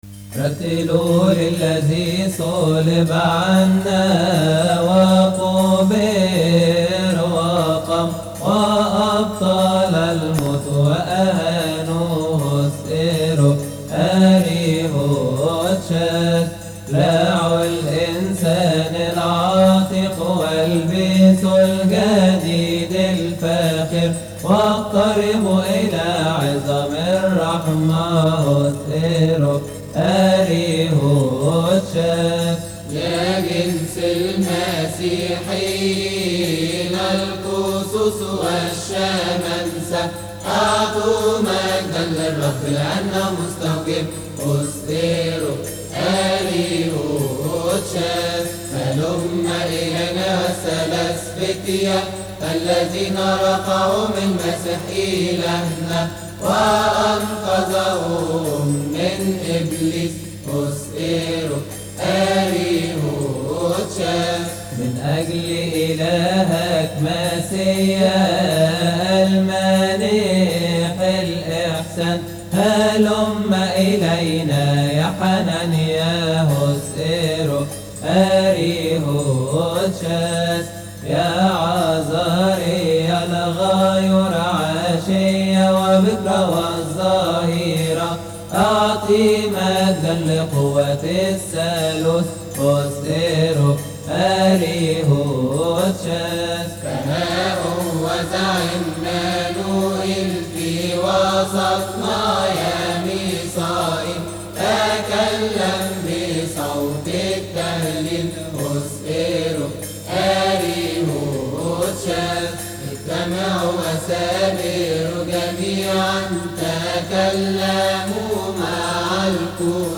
استماع وتحميل لحن أربسالين عربى من مناسبة keahk